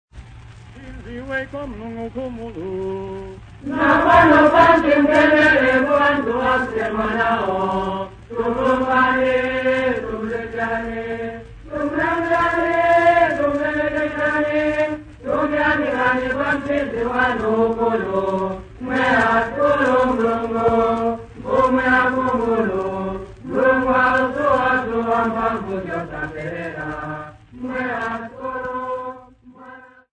Boys at St Mark's College at Mapanza
Folk Music
Field recordings
sound recording-musical
Indigenous music